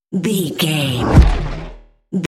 Creature dramatic hit
Sound Effects
Atonal
heavy
intense
dark
aggressive